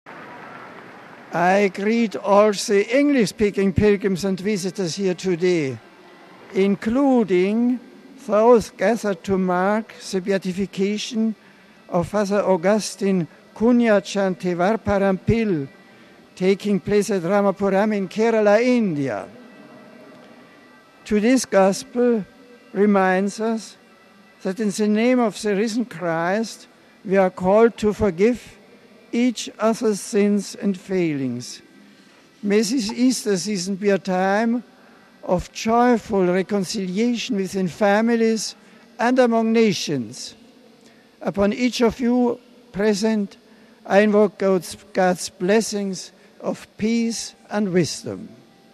After reciting the traditional midday Regina Coeli Marian prayer, the Pope greeted the people in English, recalling India’s apostle of the dalits. Numerous Indians, celebrating in Rome the beatification of ‘Kunjachan’, were present in St. Peter’s Square.